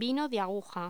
Locución: Vino de aguja
voz